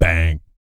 BS BANG 03-L.wav